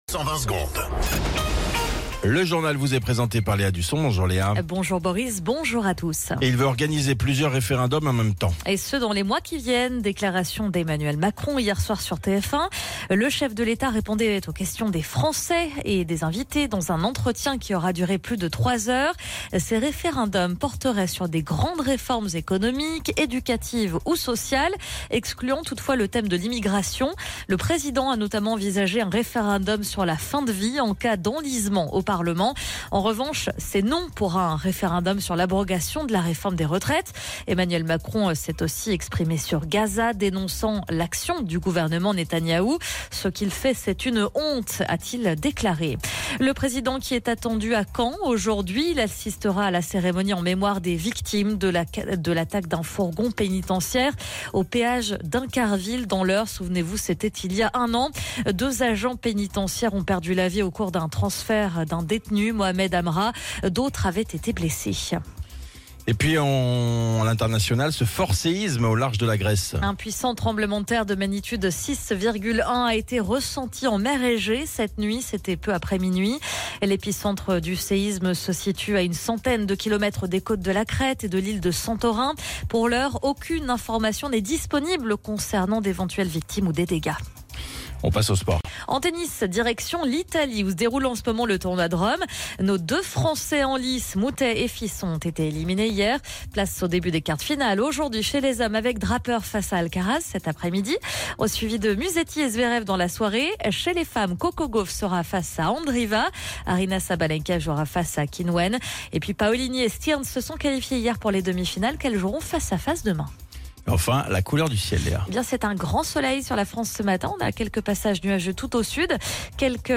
Flash Info National 14 Mai 2025 Du 14/05/2025 à 07h10 .